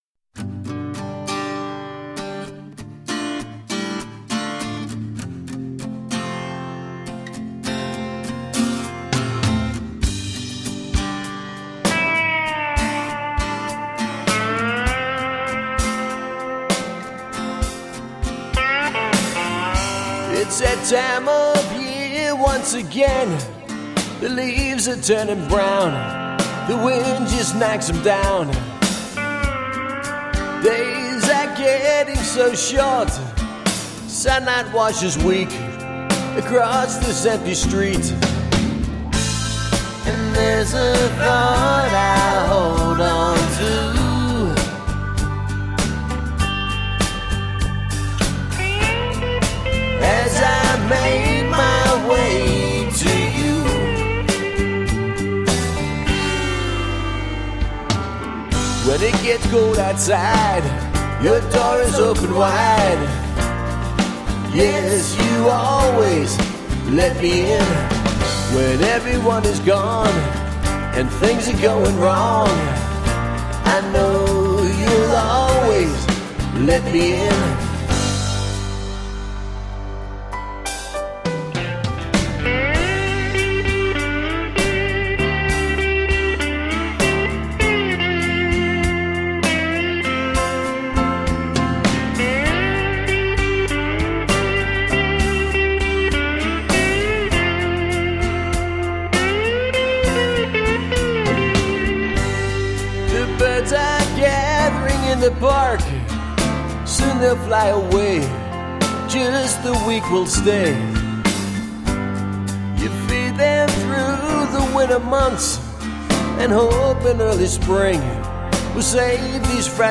Roots rock meets contemporary folk.
Tagged as: Alt Rock, Folk